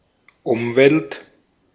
Ääntäminen
Synonyymit umbworld surroundings Ääntäminen UK US : IPA : [ɪn.ˈvaɪ.ɹən.mənt] Tuntematon aksentti: IPA : /ɪnˈvaɪɹə(n)mɪnt/ IPA : /ɪnˈvaɪɚ(n)mɪnt/ IPA : /ɪn.ˈvɑɪ.rən.mənt/ Lyhenteet ja supistumat (laki) Env't